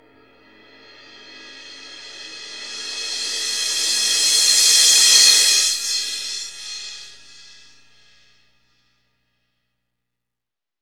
Index of /90_sSampleCDs/Roland LCDP03 Orchestral Perc/CYM_Cymbal FX/CYM_Stick Rolls
CYM CRES 03L.wav